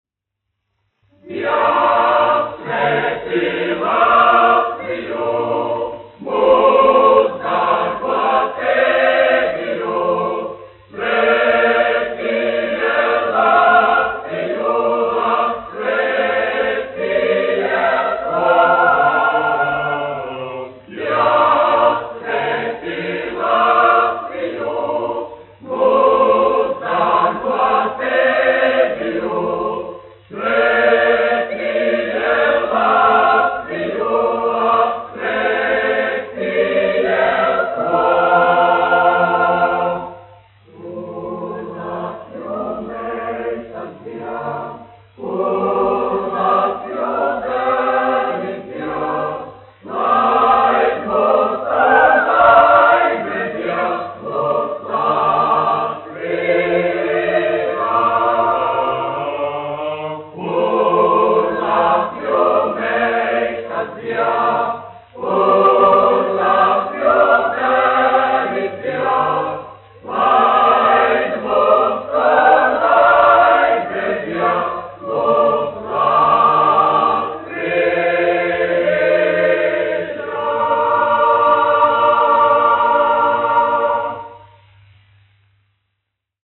1 skpl. : analogs, 78 apgr/min, mono ; 25 cm
Nacionālās dziesmas un himnas
Kori (jauktie)
Latvijas vēsturiskie šellaka skaņuplašu ieraksti (Kolekcija)